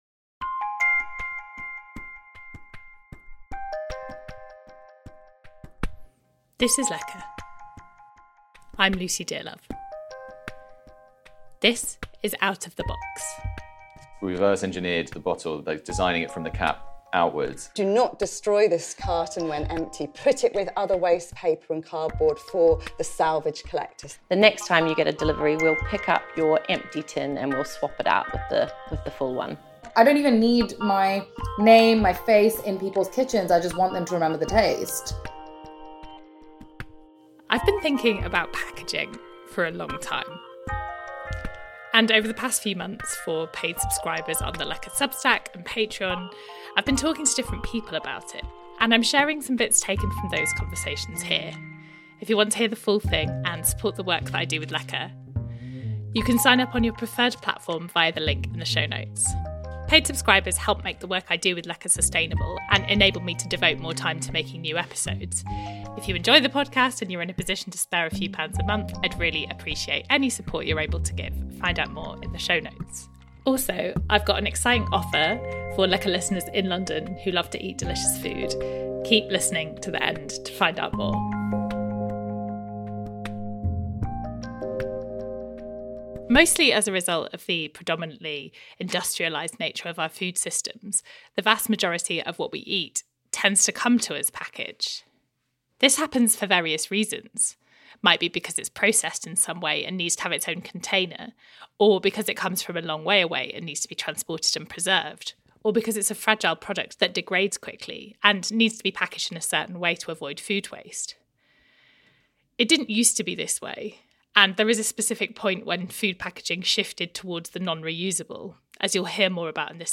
This episode features excerpts from longer conversations published for paid subscribers on Substack and Patreon.